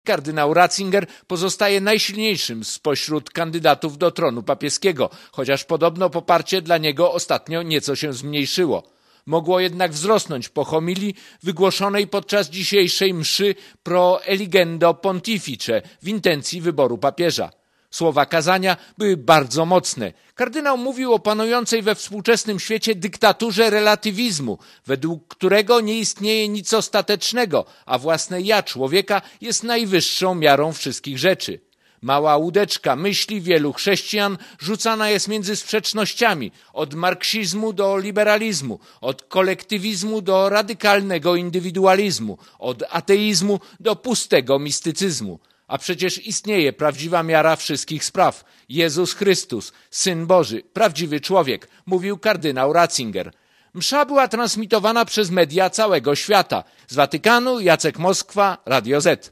msza_przed_konklawe.mp3